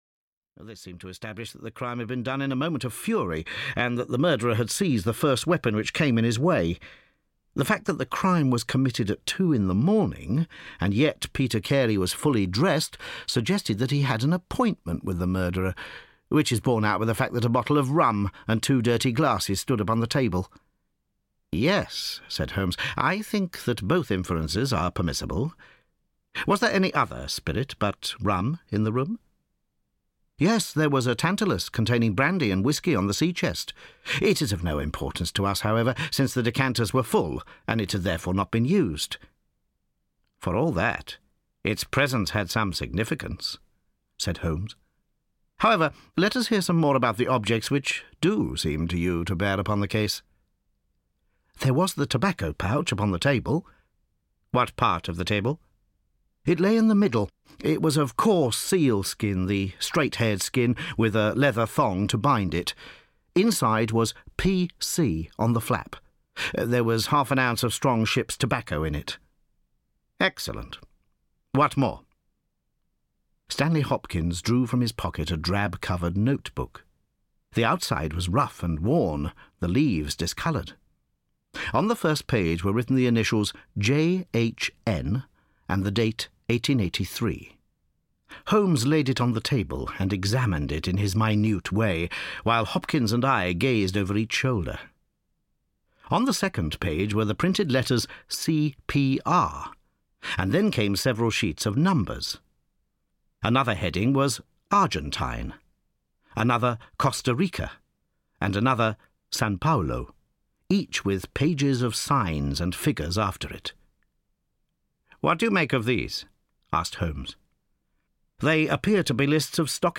Audio knihaThe Return of Sherlock Holmes – Volume III (EN)
Ukázka z knihy